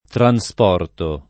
trasporto [ tra S p 0 rto ]